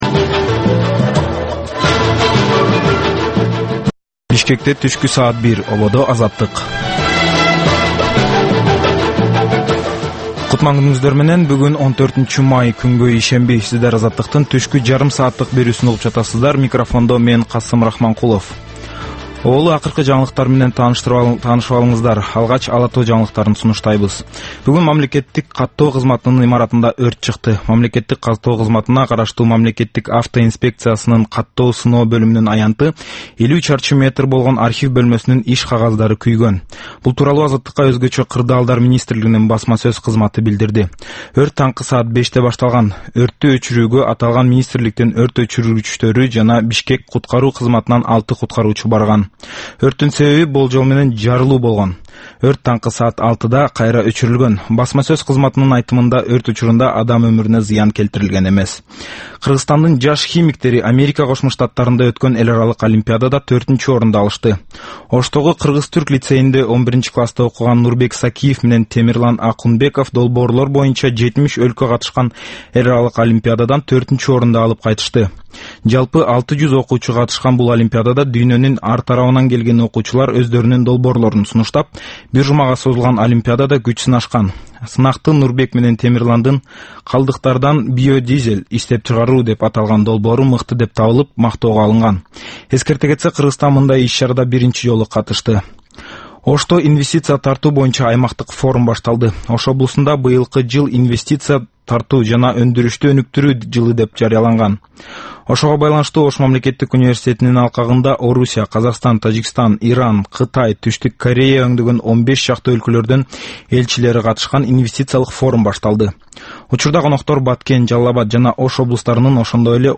Түшкү саат 1деги кабарлар
"Азаттык үналгысынын" күндөлүк кабарлар топтому Ала-Тоодогу, Борбордук Азиядагы жана дүйнөдөгү эң соңку жаңылыктардан турат. Кабарлардын бул топтому «Азаттык үналгысынын» оригиналдуу берүүсү обого чыккан сааттардын алгачкы беш мүнөтүндө сунушталат.